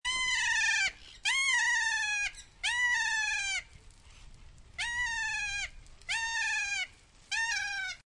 Lori Arcoíris (Trichoglossus moluccanus)
Comportamiento: Son aves muy activas y ruidosas.